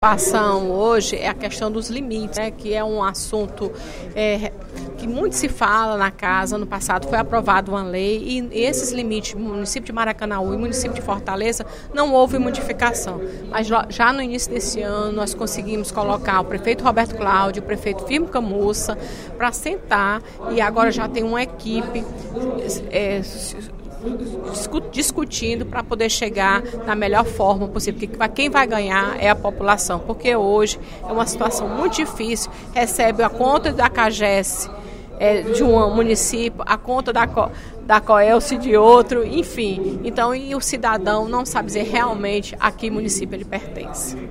A deputada Fernanda Pessoa (PR) salientou, durante o primeiro expediente da sessão plenária desta terça-feira (07/02) a importância de resolver a divisão dos limites geográficos entre Maracanaú e Fortaleza.